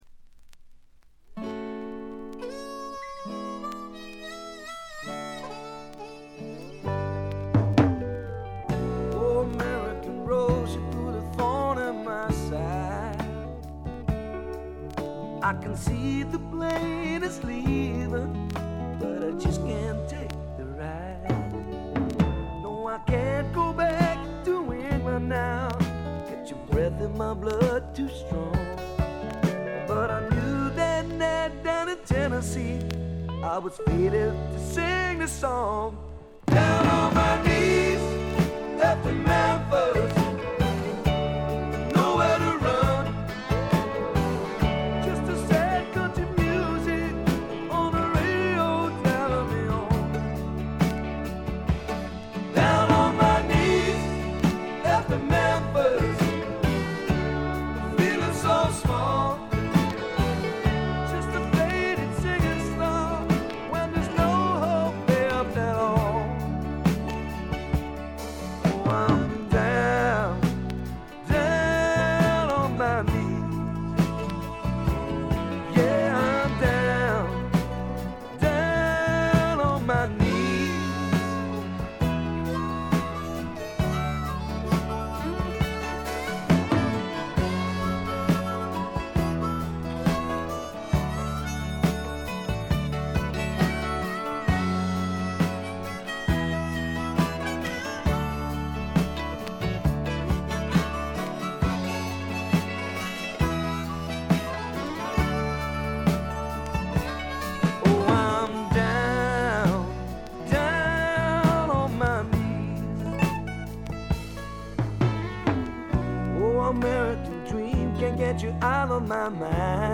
軽微なチリプチ。散発的なプツ音少し。
パブロック＆英国スワンプ名作！
試聴曲は現品からの取り込み音源です。